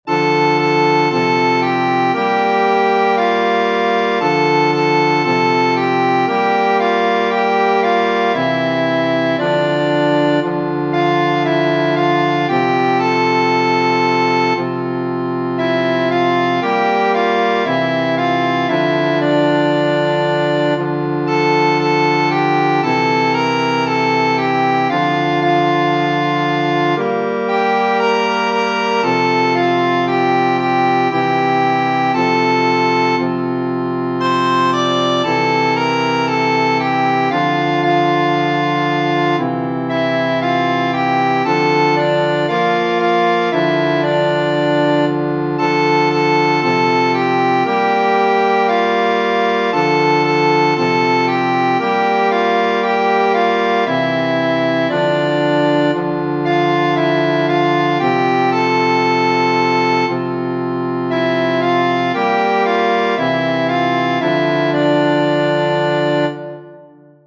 Tradizionale Genere: Religiose Parce, Domine, parce populo tuo: ne in aeternum irascaris nobis. 1.